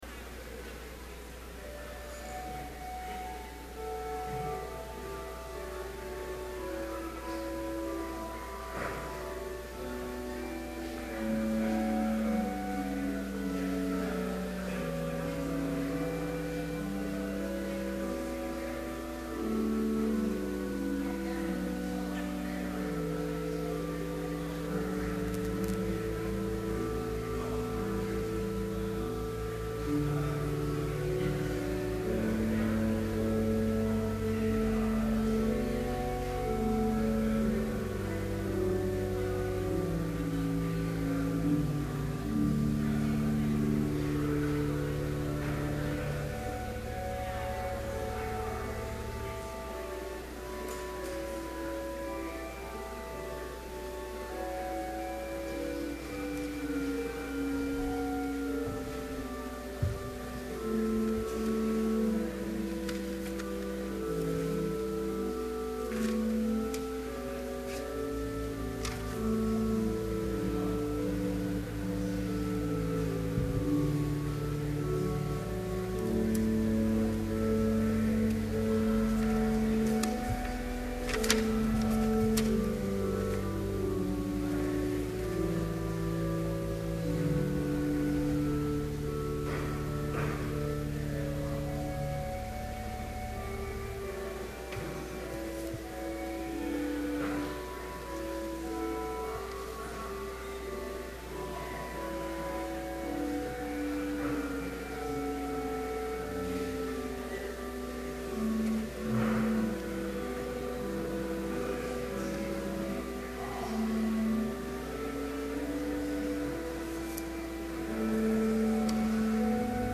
Complete service audio for Chapel - January 12, 2012